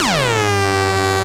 BWB 6 SOUND (SIZZLE DOWN).wav